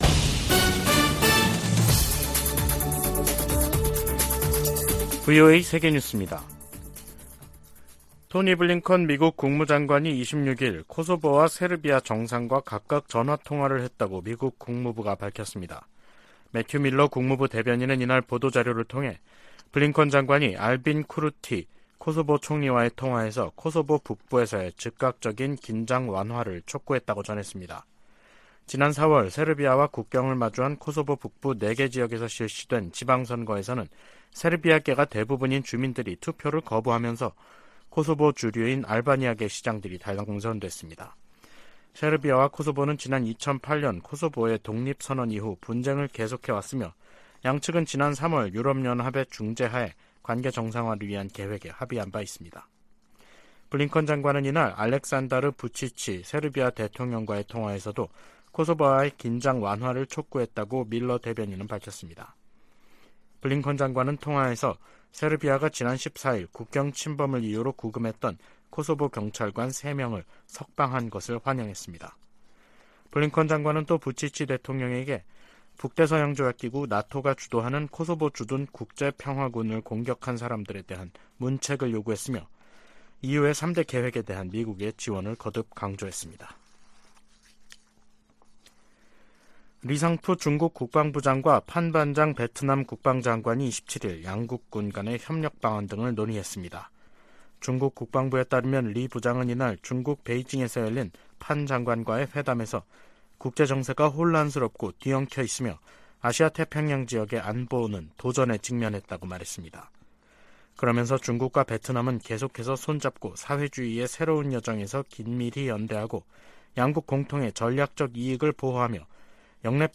VOA 한국어 간판 뉴스 프로그램 '뉴스 투데이', 2023년 6월 27일 3부 방송입니다. 한반도 긴장 고조 책임이 미국에 있다고 북한이 비난한데 대해 미 국무부는 역내 긴장을 고조시키는 건 북한의 도발이라고 반박했습니다. 미 국무부는 러시아 용병기업 바그너 그룹의 무장 반란 사태가 바그너와 북한 간 관계에 미칠 영향을 판단하기는 이르다고 밝혔습니다. 백악관 인도태평양조정관은 한국 등 동맹과의 긴밀한 관계가 인도태평양 전체의 이익에 부합한다고 말했습니다.